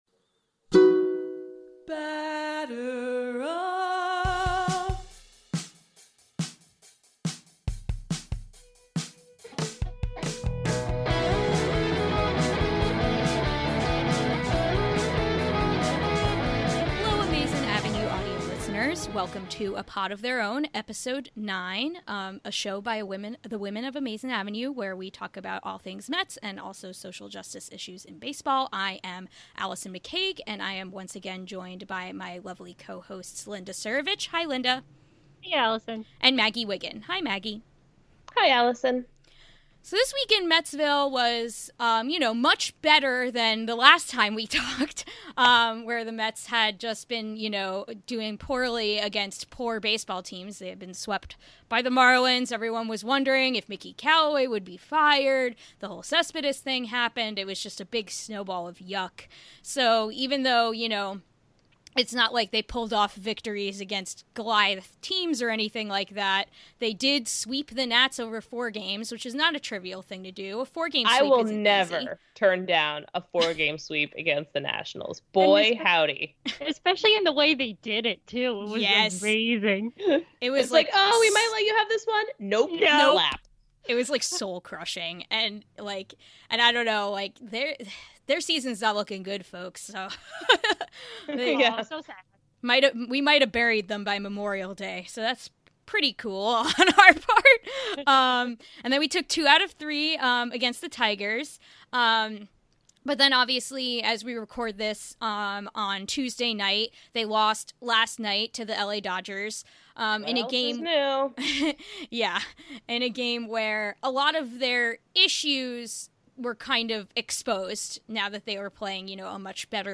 Welcome back to A Pod of Their Own, a show by the women of Amazin’ Avenue where we talk all things Mets, social justice issues in baseball, and normalize female voices in the sports podcasting space.